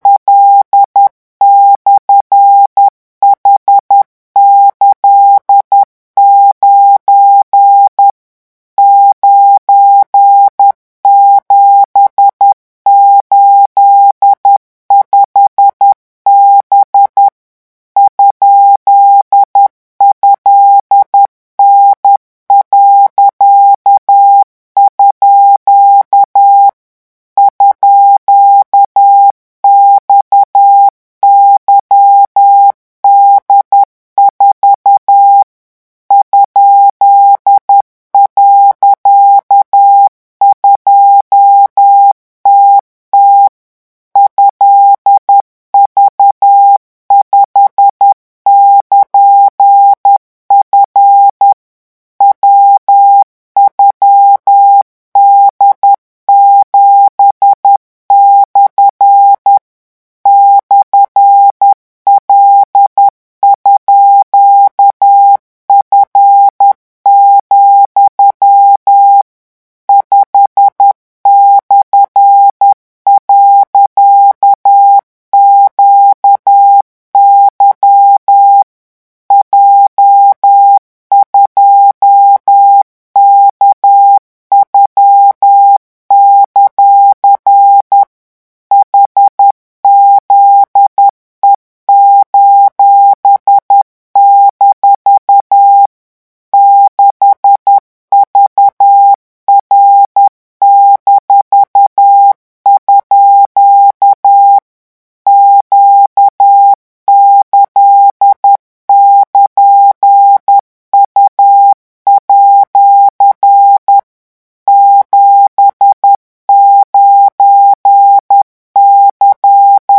CODE MORSE-REVISION 18
C'est parti - vitesse de ... 10 mots minutes : 14 mots minutes : 18 mots minutes : 22 mots minutes : REVISION 18 - 10 mots minute REVISION 18 - 14 mots minute REVISION 18 - 18 mots minute REVISION 18 - 22 mots minute
revision18-vitesse_10_mots.mp3